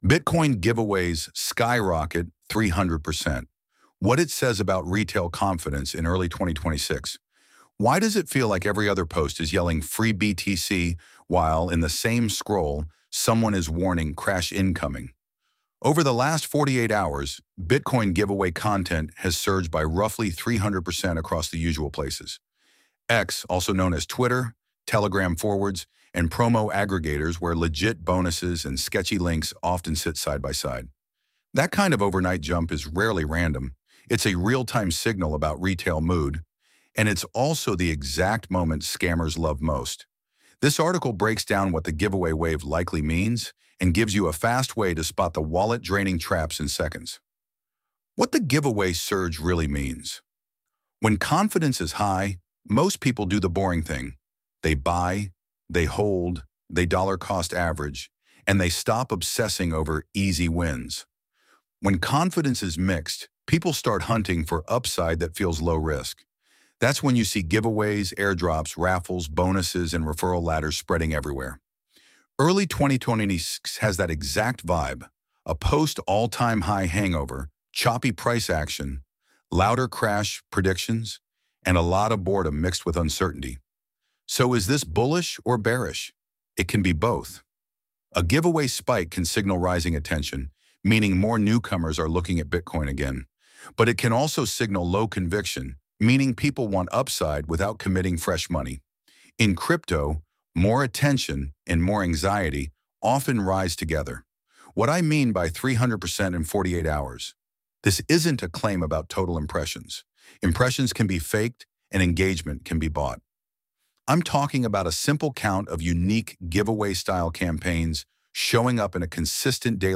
audio-Bitcoin-Giveaways-Skyrocket-300-article-read.mp3